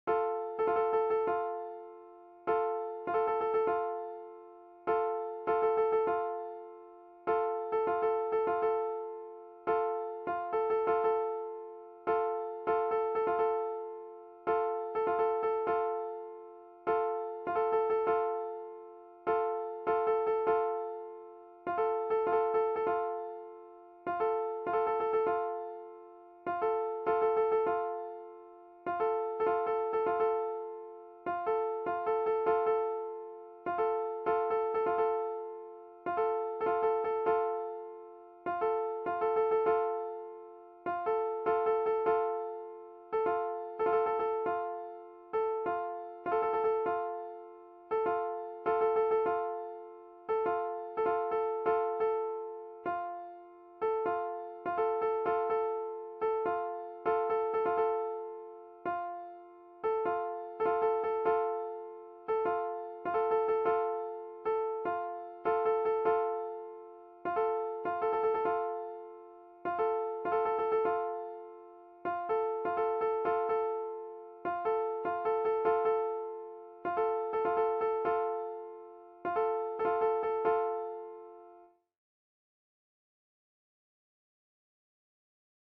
The top part follows the bottom part which is the beat.